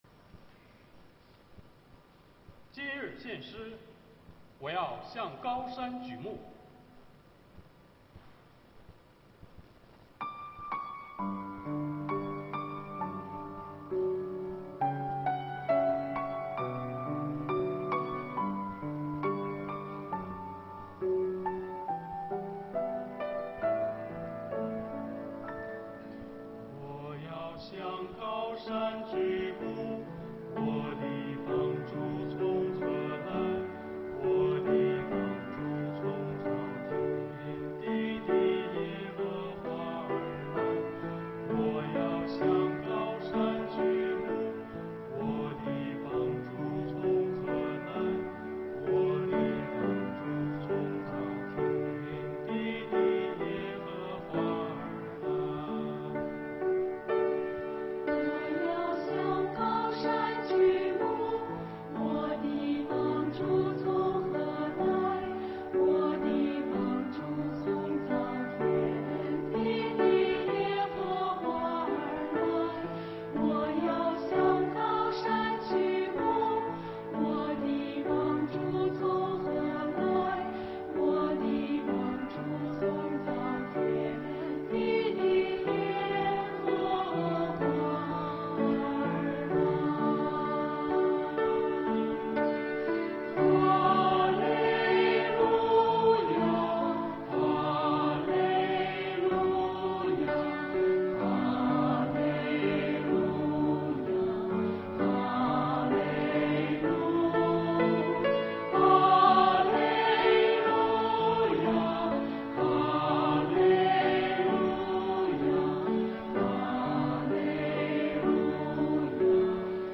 青年诗班
诗班献诗